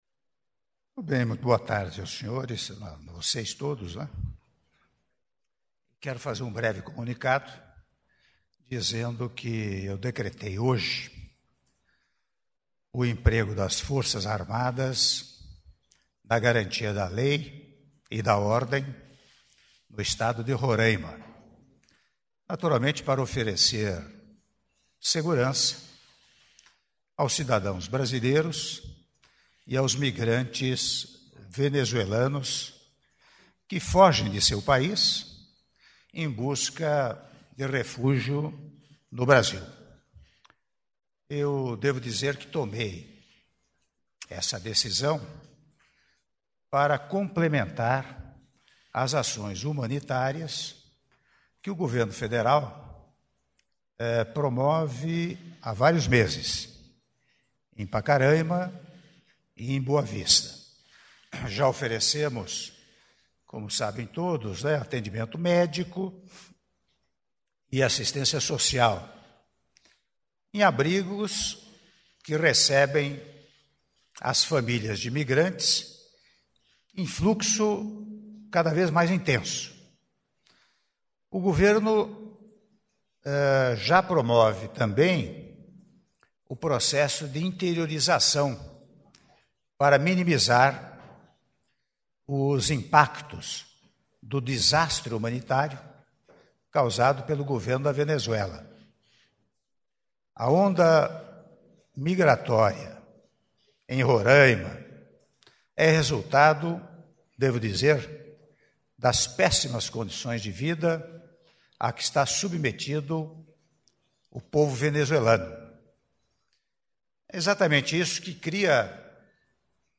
Áudio da Declaração à Imprensa do Presidente da República, Michel Temer - (04min04s)